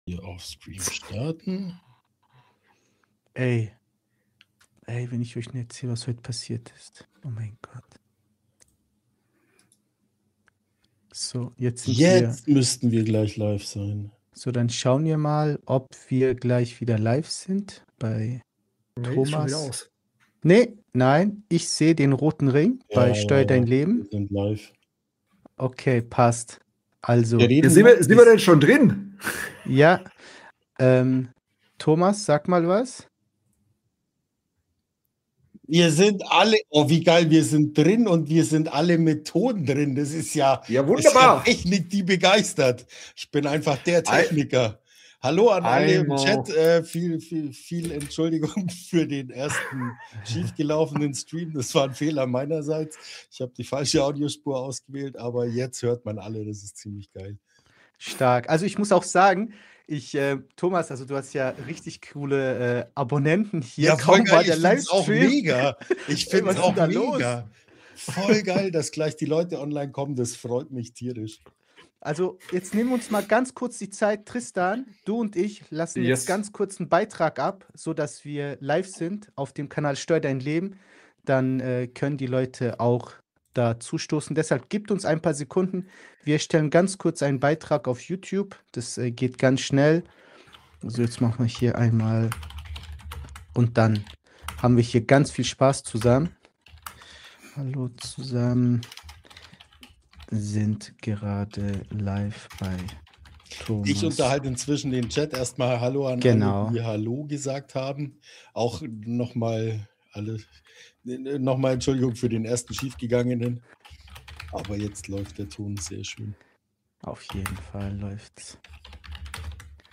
Eigentlich wollten wir nur einen 10 Minuten Test Run für unseren ersten Live Podcast machen .....